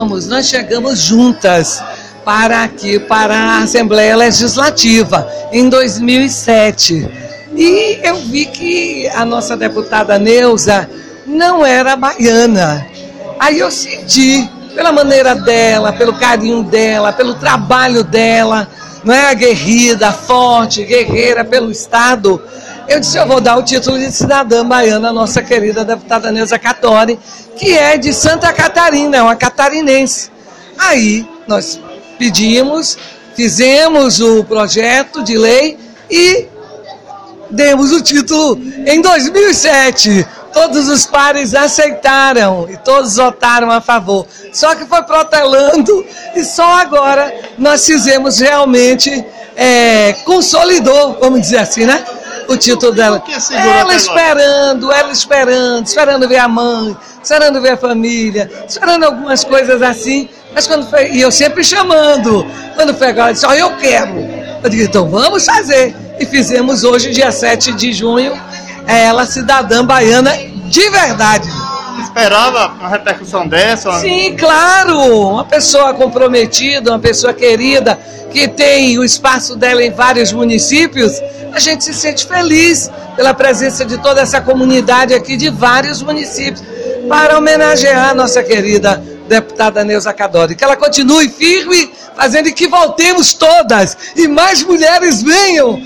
Esta trajetória de Neusa Cadore foi narrada na manhã desta quinta-feira, 07, na Assembleia Legislativa da Bahia – ALBA a Sessão Especial para entrega de Titulo de Cidadã Baiana de autoria da deputada Ângela Souza.